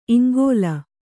♪ iŋgōla